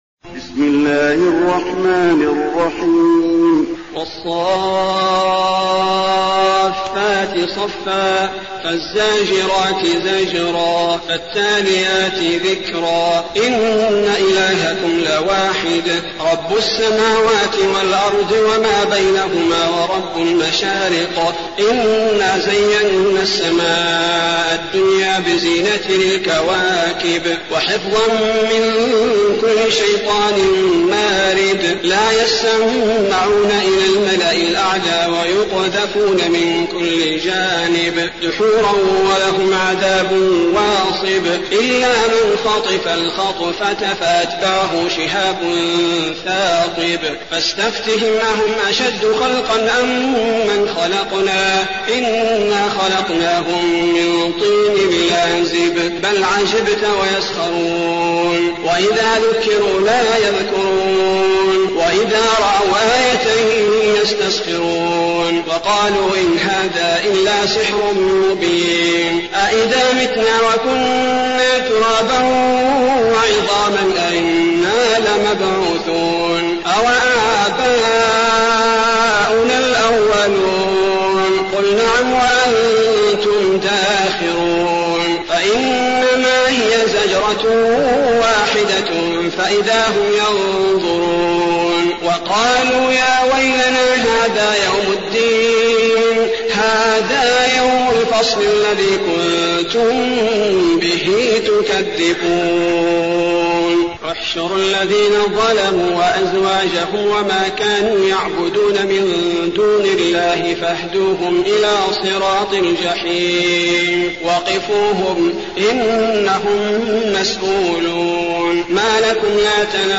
المكان: المسجد النبوي الصافات